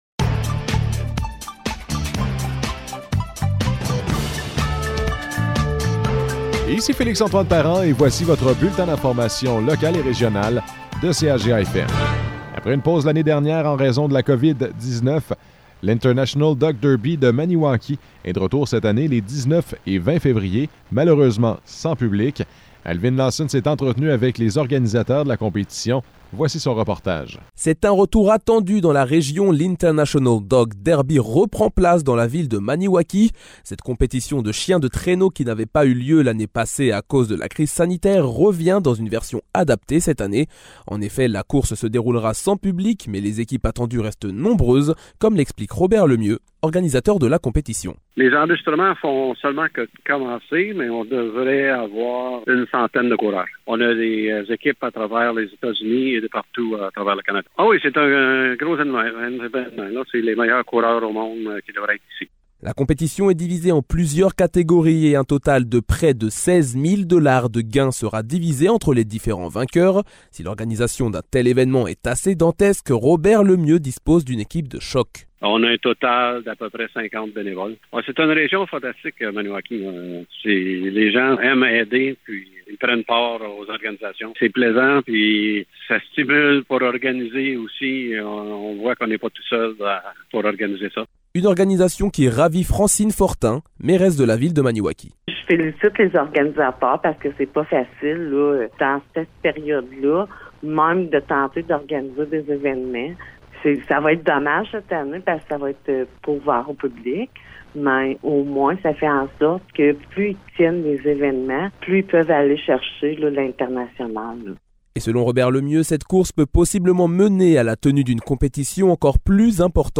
Nouvelles locales - 1er février 2022 - 12 h